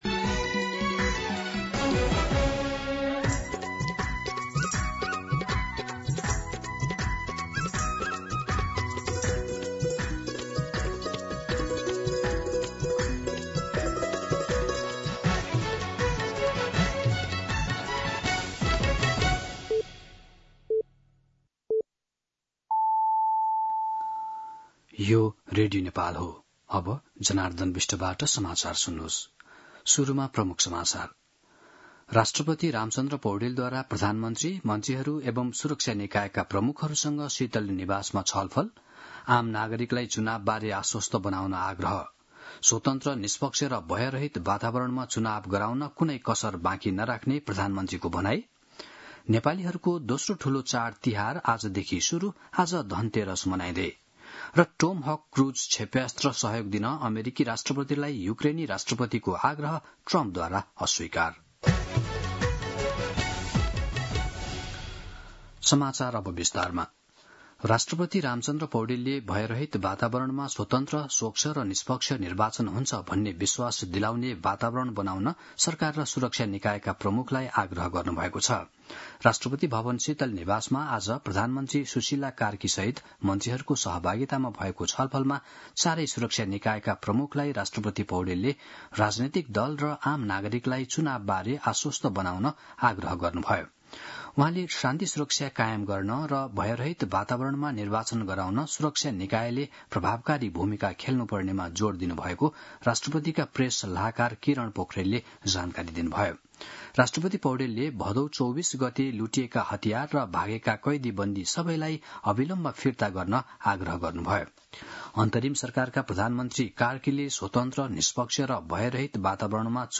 दिउँसो ३ बजेको नेपाली समाचार : १ कार्तिक , २०८२
3-pm-Nepali-News-9.mp3